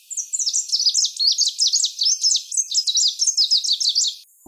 tp 10 : evolution genomes     ECE chant des oiseaux
chant Viridanus